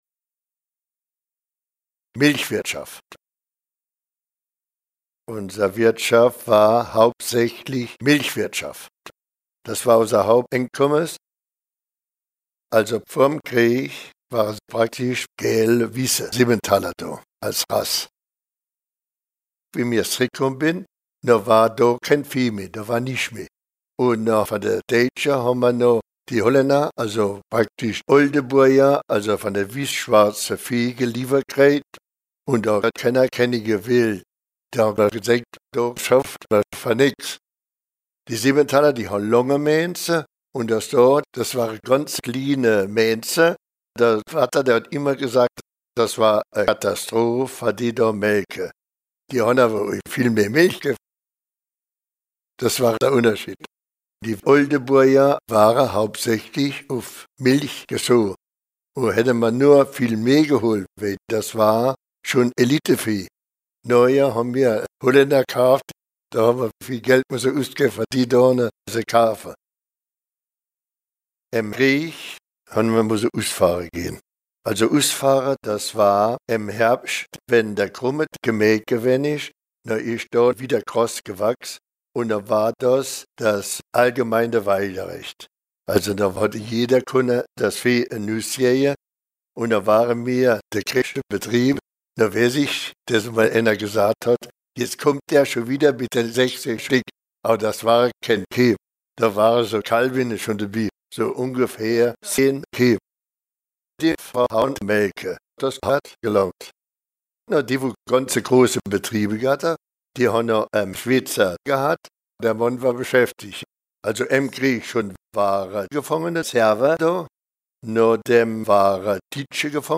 Contes et récits en ditsch enregistrés dans les communes de Linstroff, Grostenquin, Bistroff, Erstroff, Gréning, Freybouse, Petit Tenquin-Encheville, Petit Tenquin et Hellimer-Grostenquin.